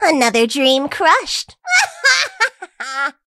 willow_kill_vo_09.ogg